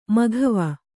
♪ maghava